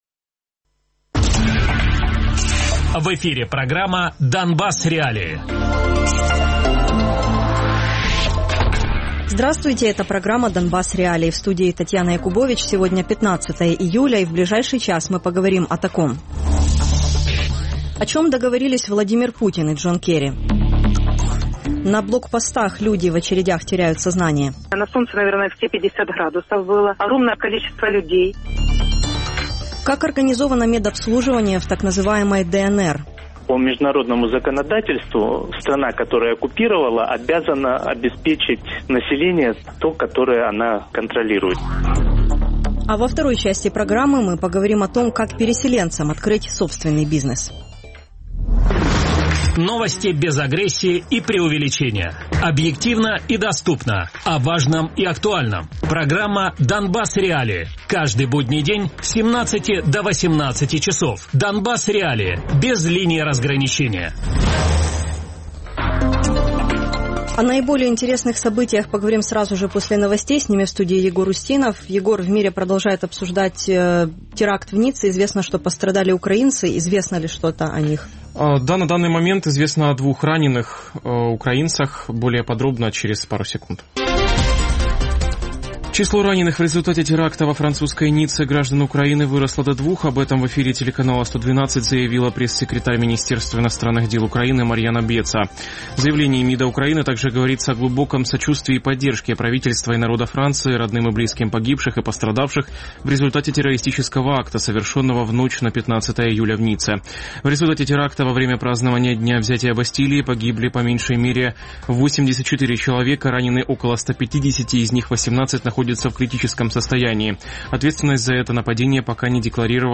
предприниматель и переселенка Радіопрограма «Донбас.Реалії» - у будні з 17:00 до 18:00.